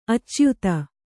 ♪ acyuta